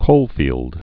(kōlfēld)